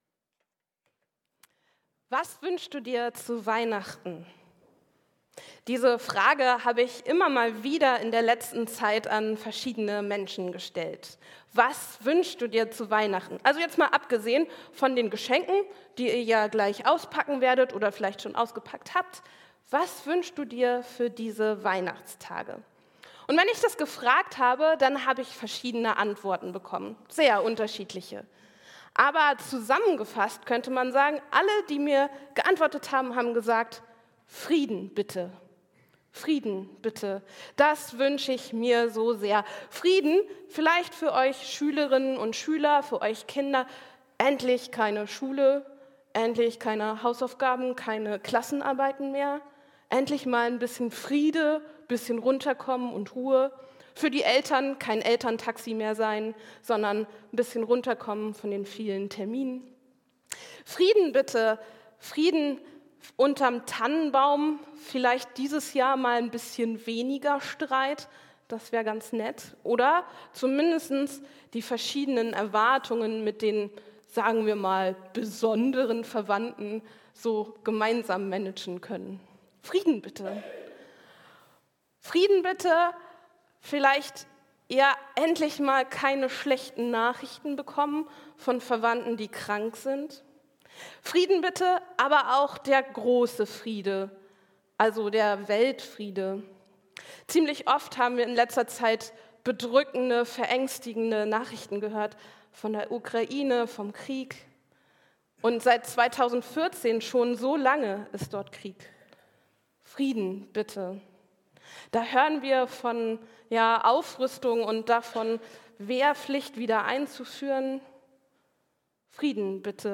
Gib das Licht weiter! ~ Christuskirche Uetersen Predigt-Podcast Podcast